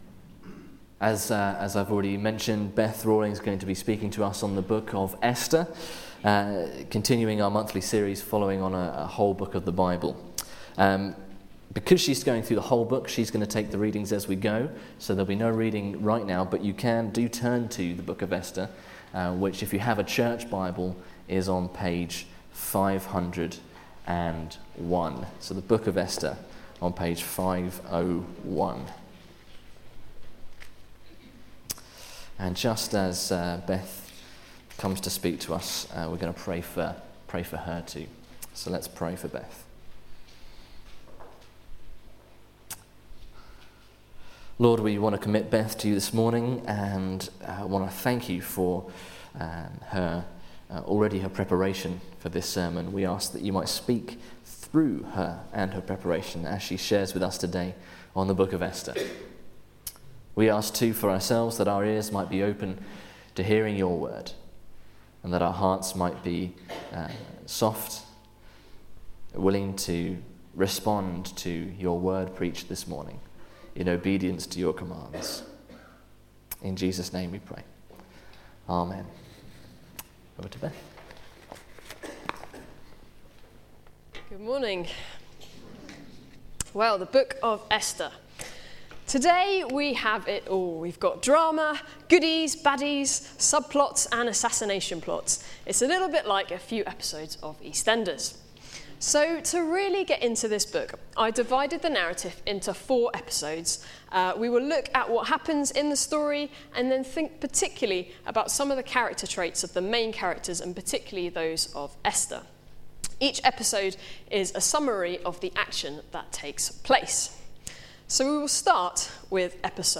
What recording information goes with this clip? Media for Sunday Service on Sun 07th Sep 2014 11:00